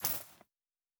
Coin and Purse 01.wav